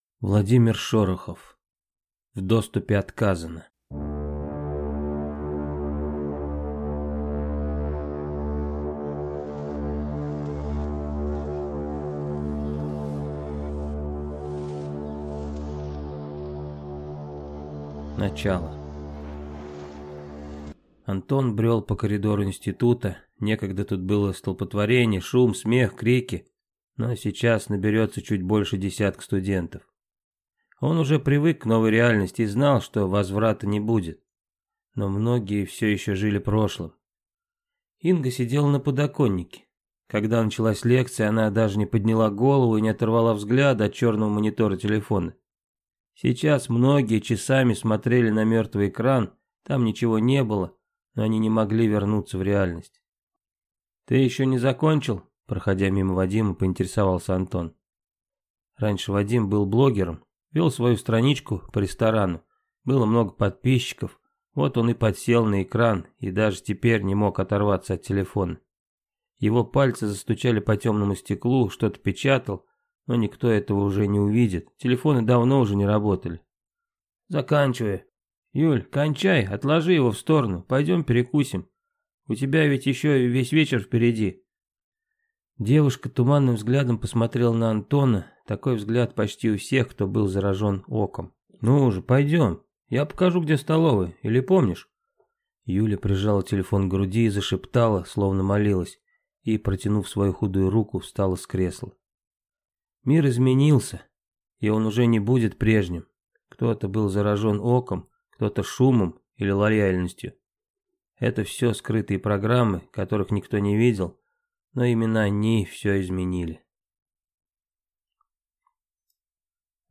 Аудиокнига В доступе отказано | Библиотека аудиокниг